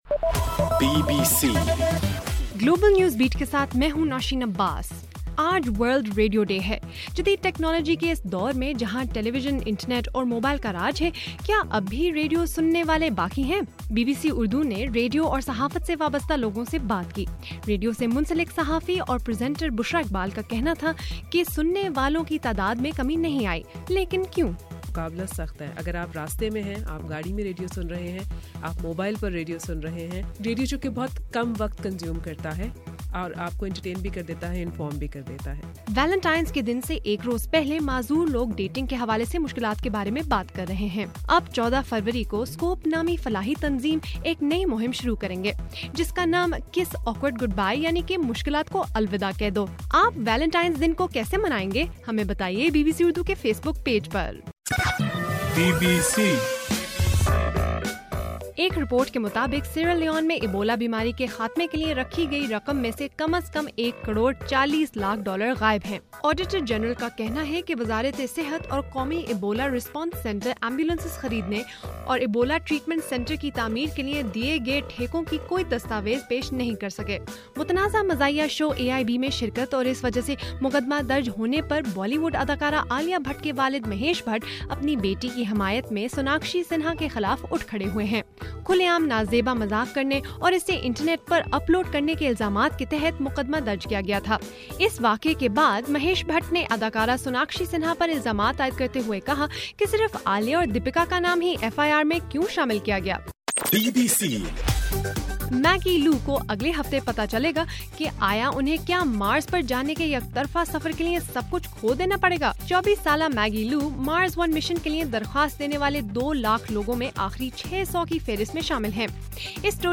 فروری 14: صبح 1 بجے کا گلوبل نیوز بیٹ بُلیٹن